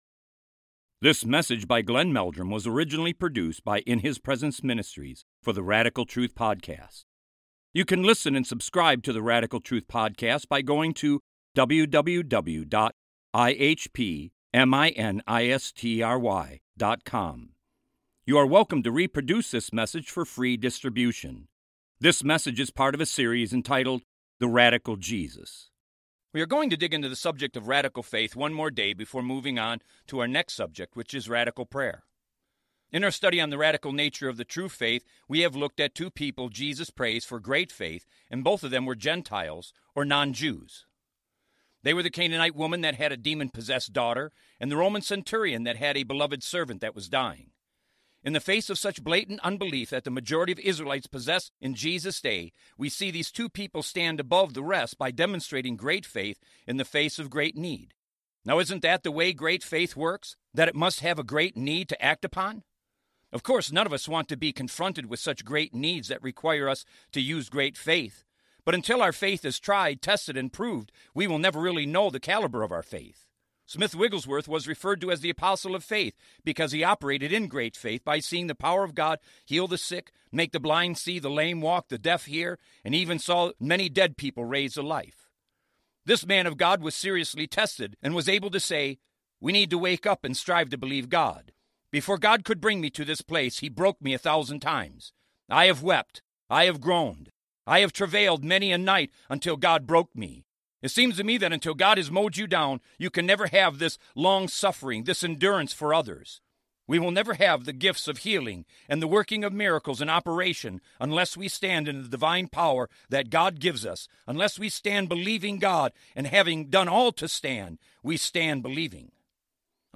In this sermon, the preacher emphasizes the importance of trusting in God, even when it feels scary or uncertain. He highlights that the alternative to trusting God is disbelief or disobedience, which can lead to broken relationships and families falling apart.